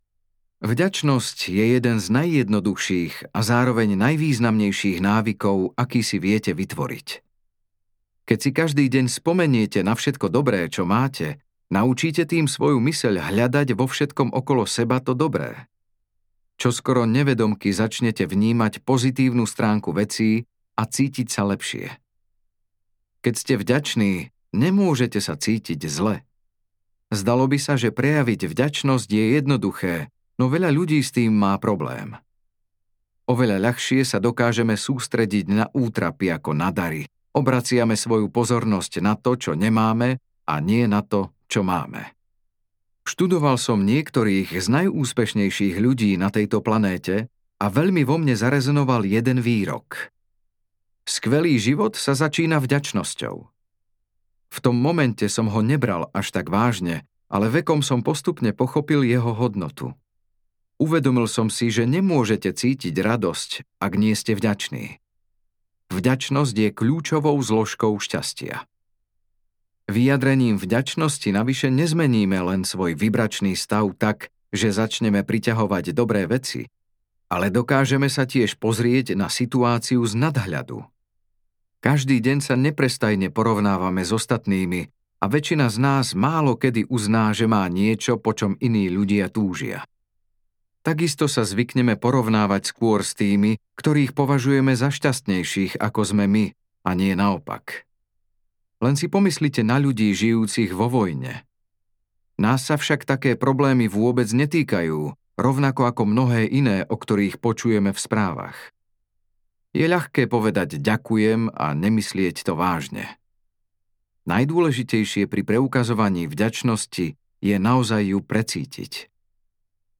Dobré vibrácie, dobrý život audiokniha
Ukázka z knihy